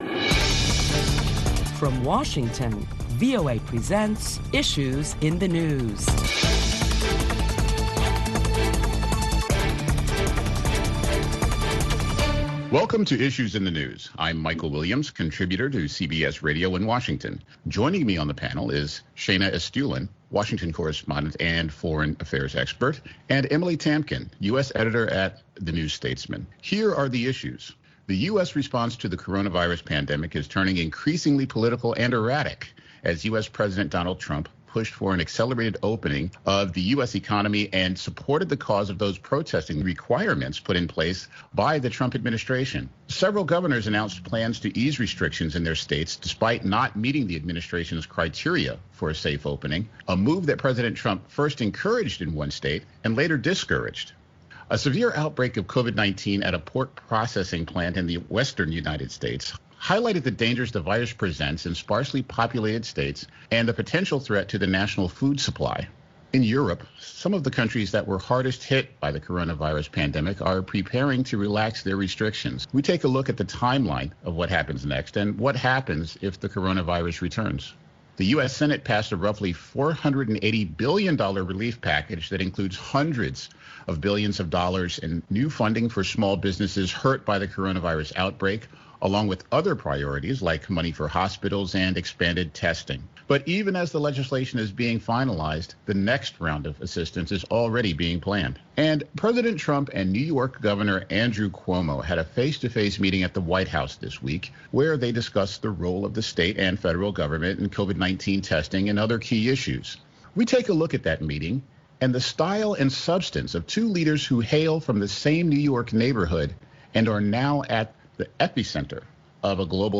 Listen to a panel of prominent Washington journalists as they deliberate the latest top stories of the week which include Americans in several states protest to reopen their states for business as COVID-19 has claimed over 50,000 American lives.